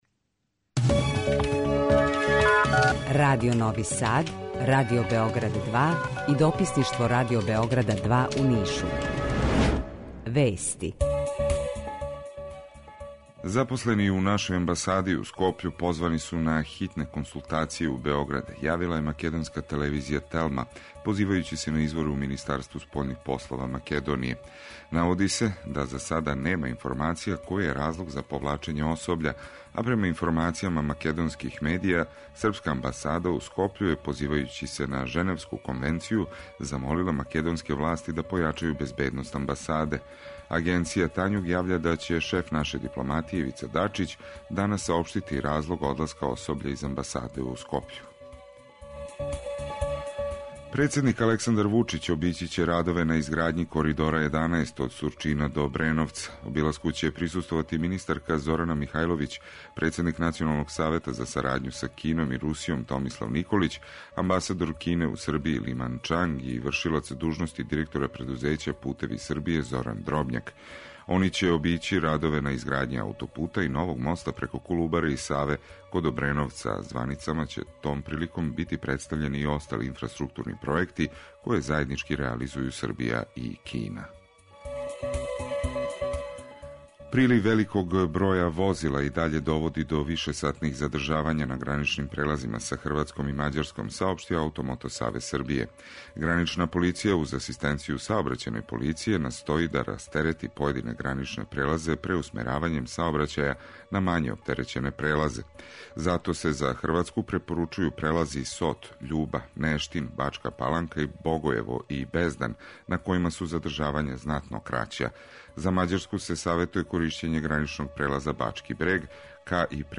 Jутарњи програм заједнички реализују Радио Београд 2, Радио Нови Сад и дописништво Радио Београда из Ниша
У два сата, ту је и добра музика, другачија у односу на остале радио-станице.